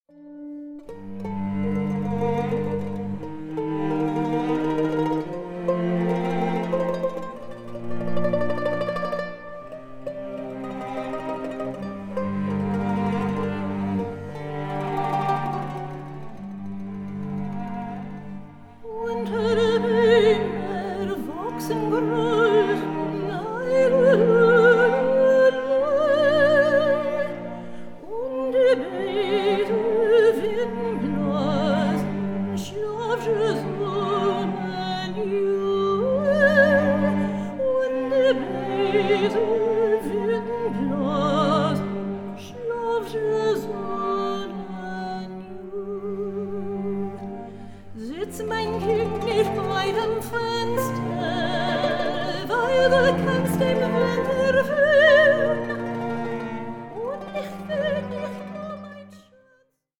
HAUNTING, GENTLE SPIRITS, DREAMS, AND LULLABIES
all above a rich bed of cellos